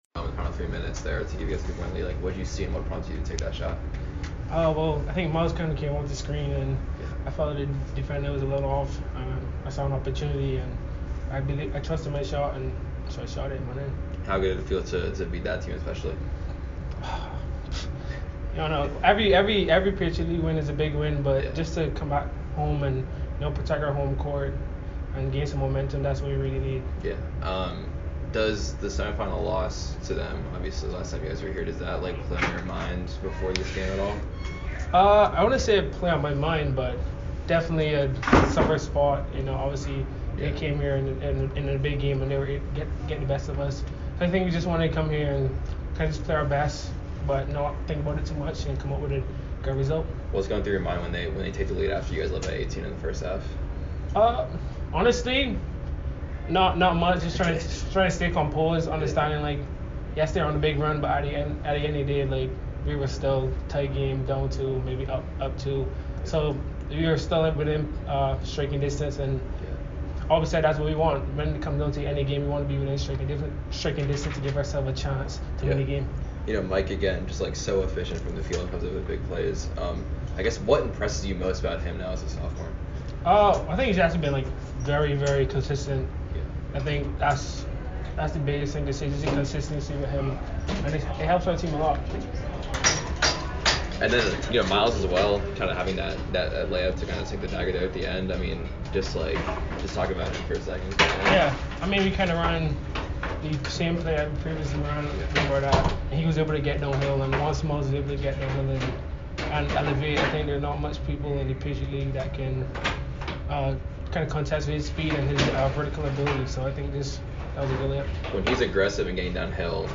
Men's Basketball / Lehigh Postgame Interview (1-15-25) - Boston University Athletics